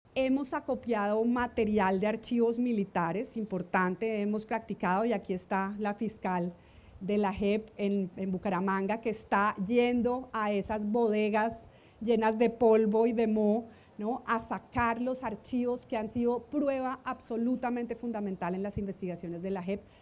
Catalina Díaz, Magistrada